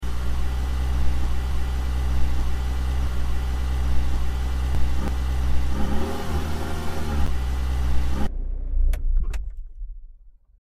car.mp3